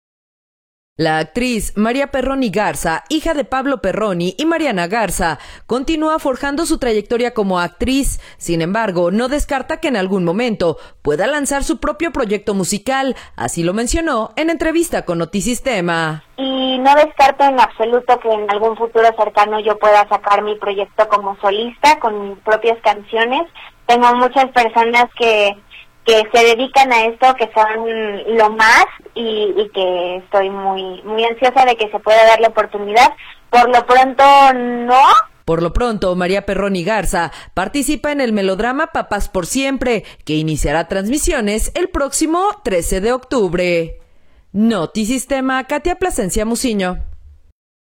La actriz María Perroni Garza, hija de Pablo Perroni y Mariana Garza, continúa forjando su trayectoria como actriz, sin embargo, no descarta que en algún momento pueda lanzar su propio proyecto musical, así lo mencionó en entrevista con Notisistema.